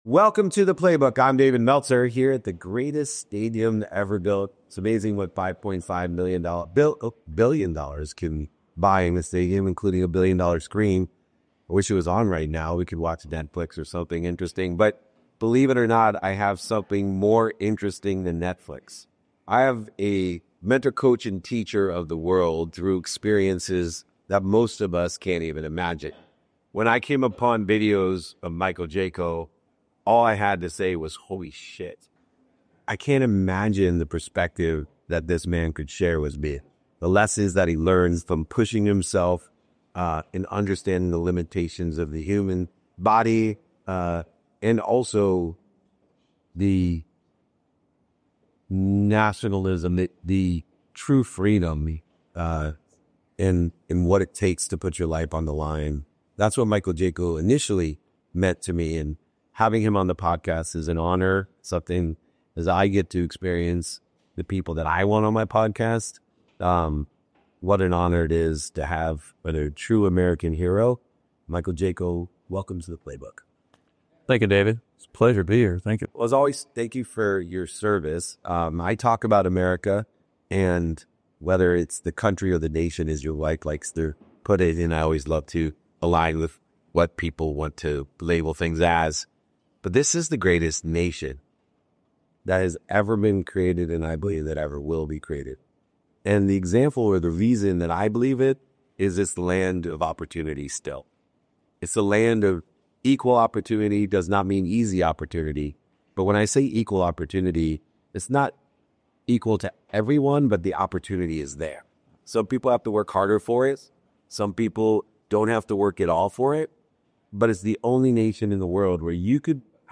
This is a conversation about belief, resilience, and activating your highest potential.